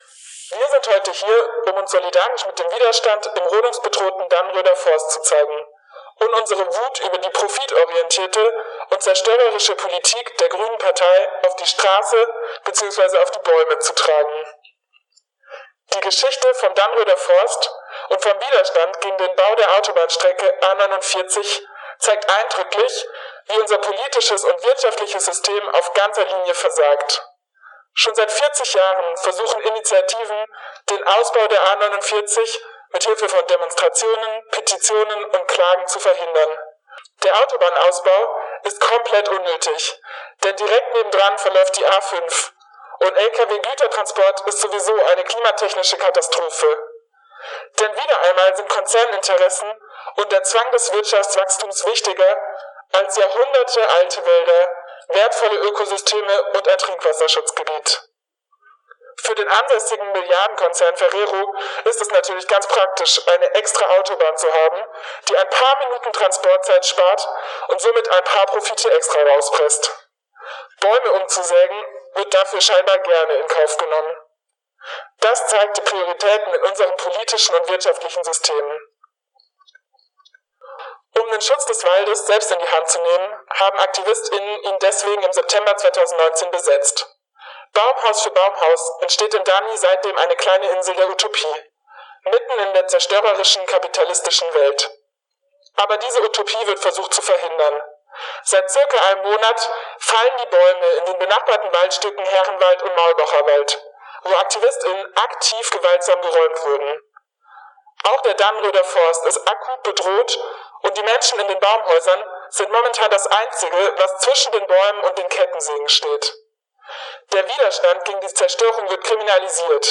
Rede Danni.mp3